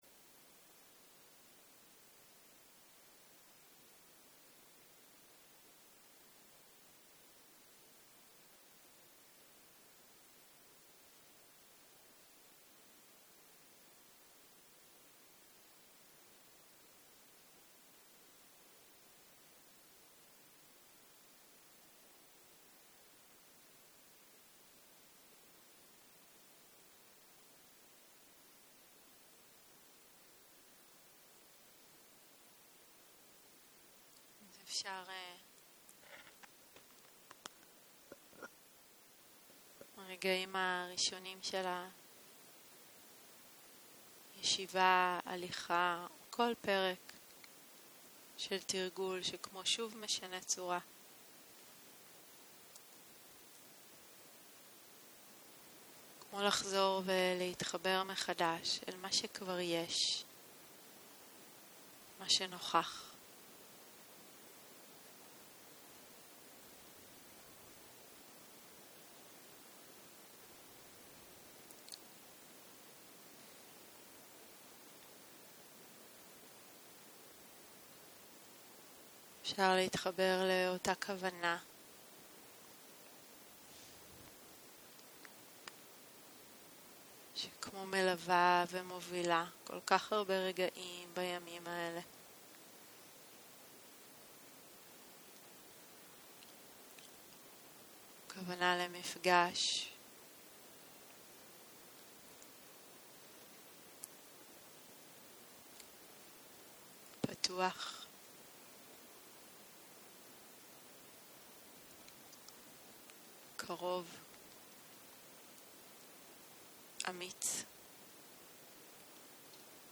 יום 6 - צהרים - מדיטציה מונחית - מודעות רחבה, היפתחות למרחב - הקלטה 15